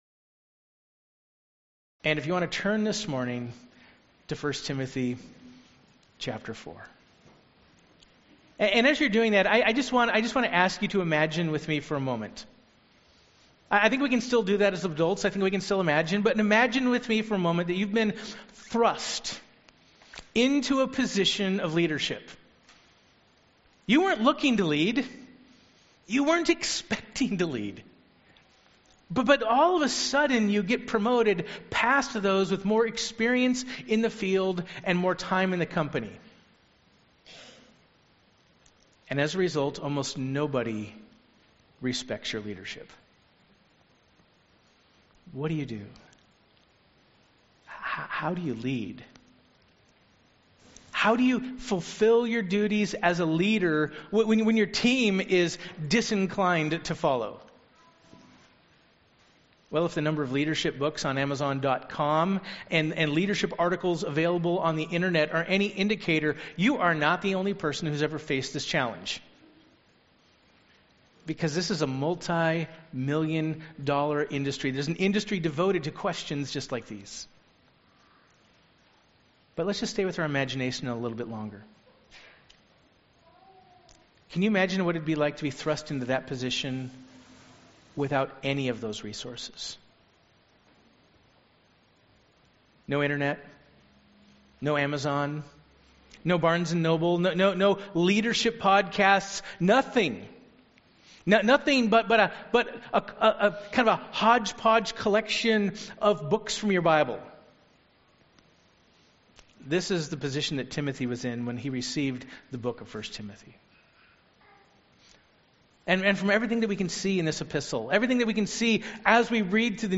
Sermon Outline: I. Faithful Leaders Understand the Impact of their Example (1 Timothy 4:11–12) II.